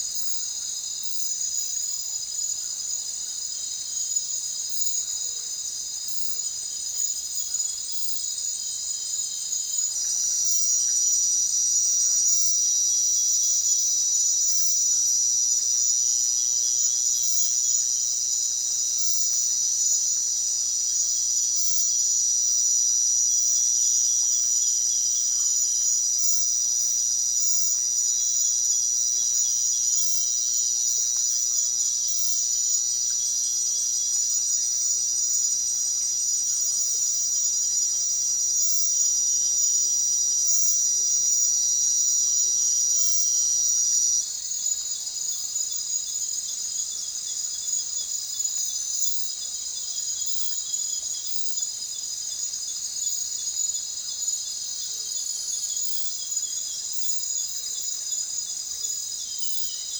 Finals-Data-Ultrasound